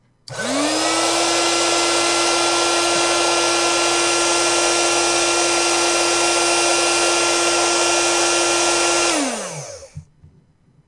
pump for air bed
描述：the noise from an air bed pump
标签： tent inflate outdoors pump air bed airbed nature
声道立体声